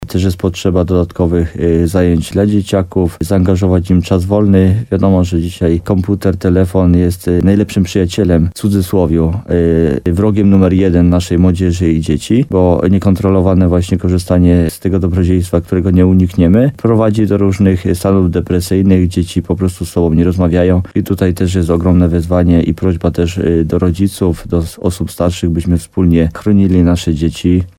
Jak zauważa wójt Jacek Migacz, bardzo ważna jest też kwestia młodego pokolenia, dlatego w świetlicy w Siołkowej dzieci i młodzież będzie mogła nie tylko spędzać czas wolny, nadrabiać zaległości w lekcjach, ale też uzyskać pomoc psychologa.